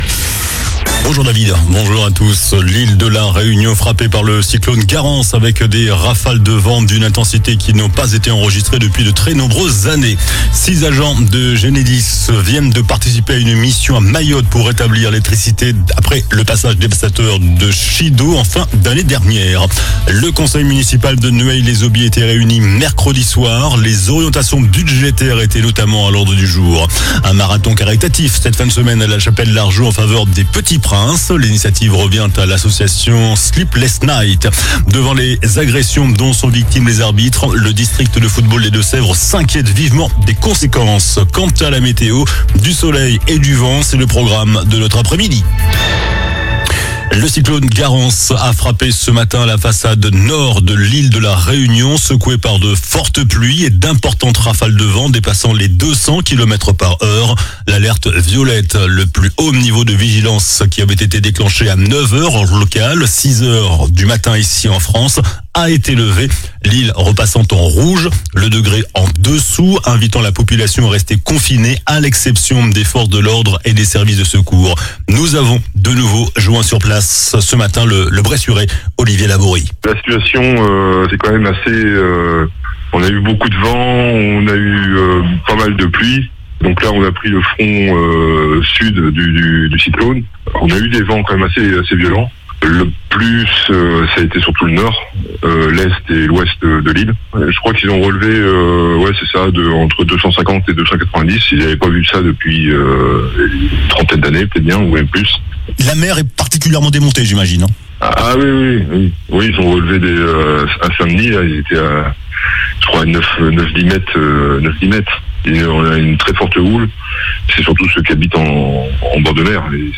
JOURNAL DU VENDREDI 28 FEVRIER ( MIDI )